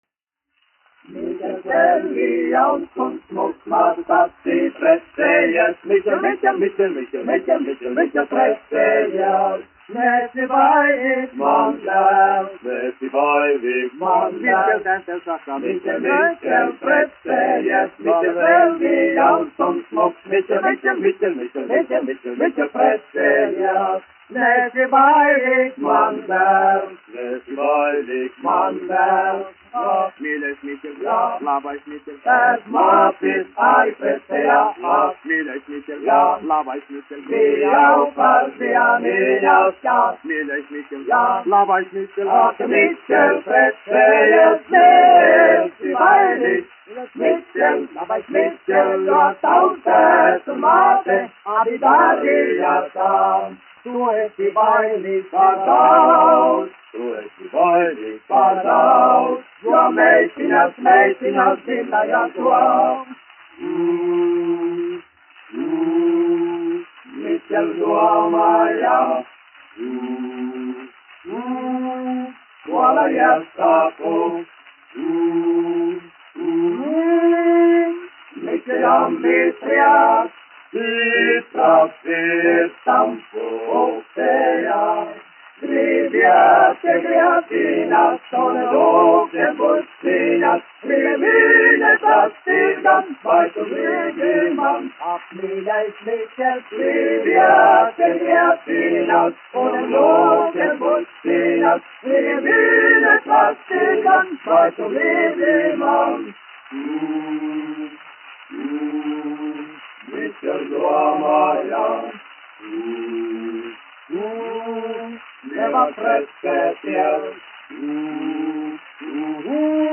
1 skpl. : analogs, 78 apgr/min, mono ; 25 cm
Vokālie seksteti
Humoristiskās dziesmas
Skaņuplate